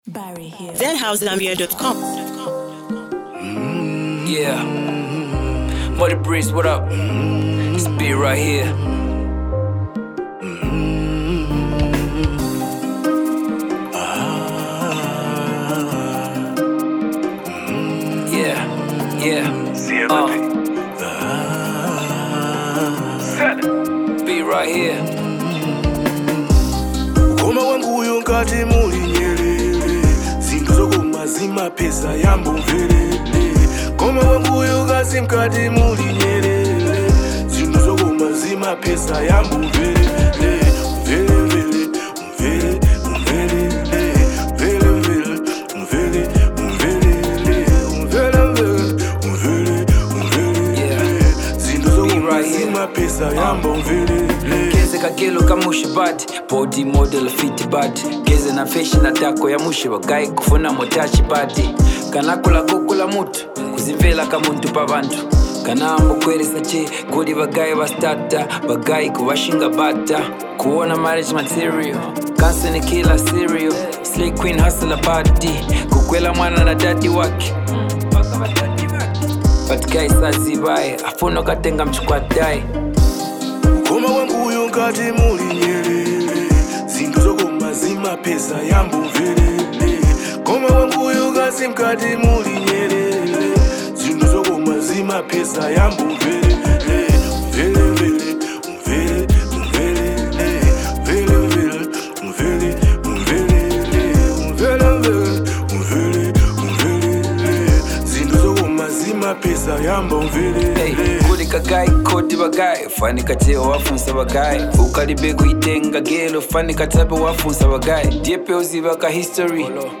this song blends emotion and melody